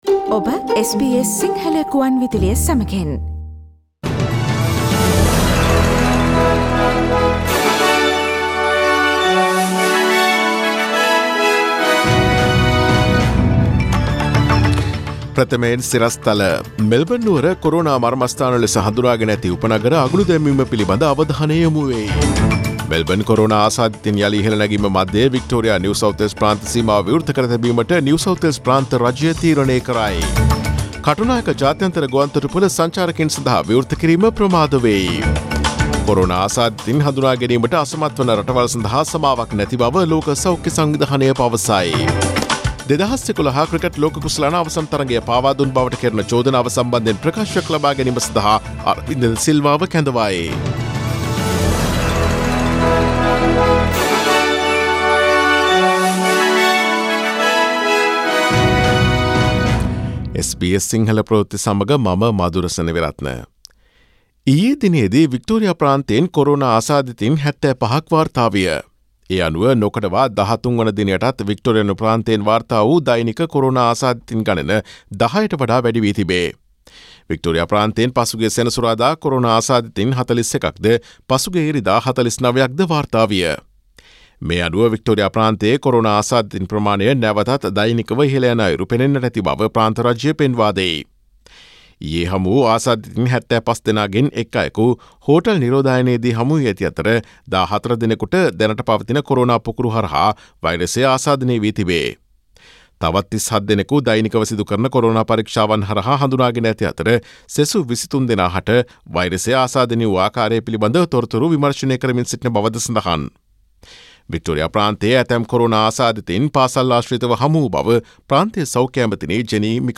Daily News bulletin of SBS Sinhala Service: Tuesday 30 June 2020